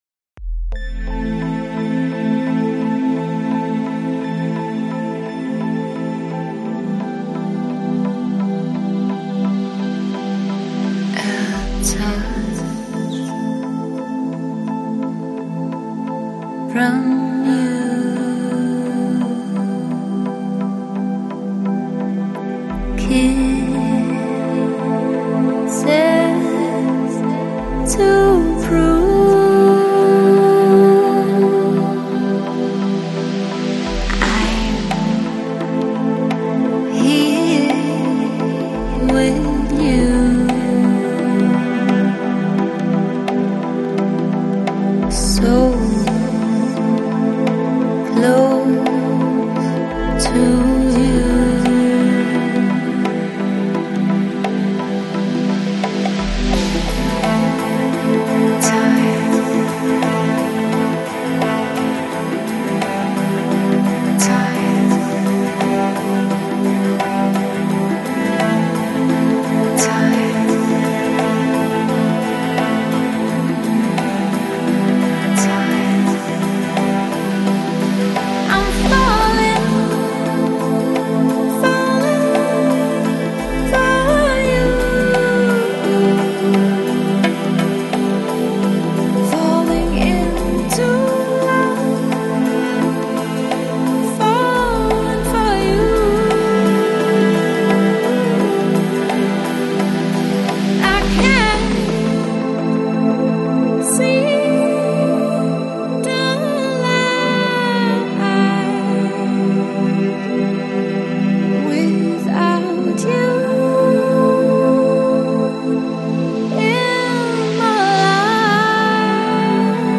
Electronic, Chill Out, Lounge, Downtempo Год издания